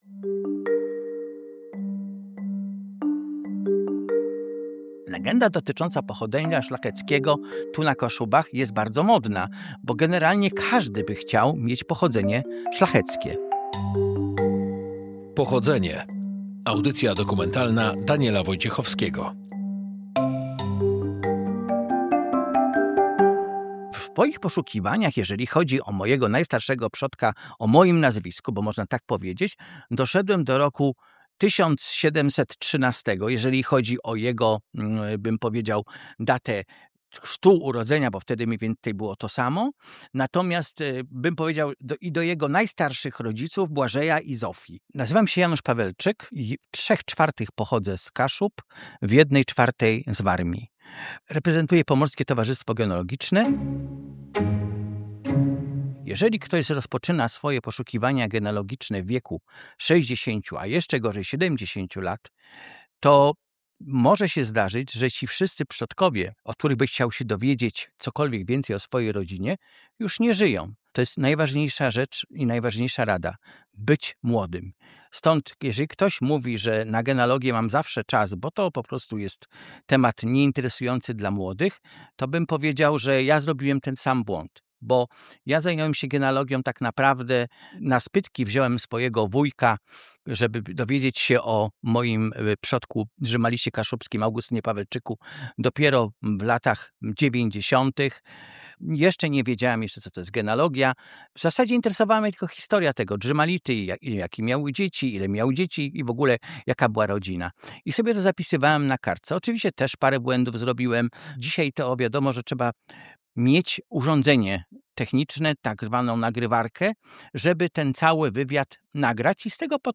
Jak rozpocząć poszukiwania własnych korzeni? Audycja dokumentalna „Pochodzenie”